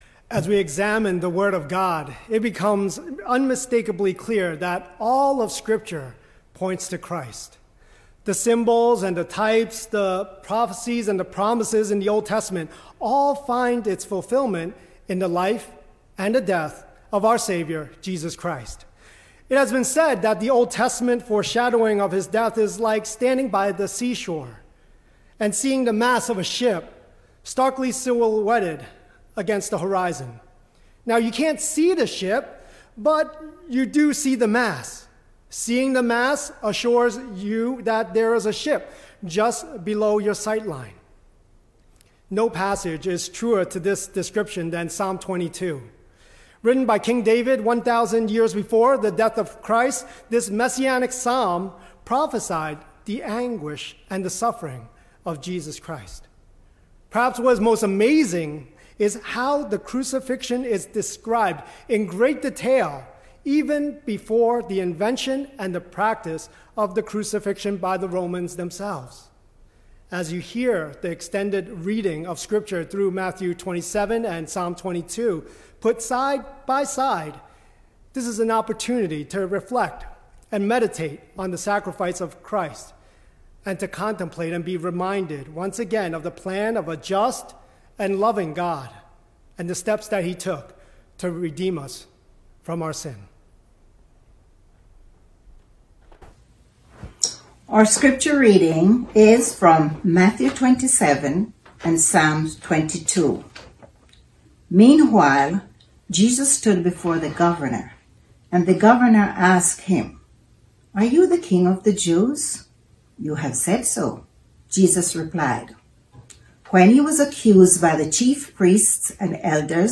Good Friday Service 2020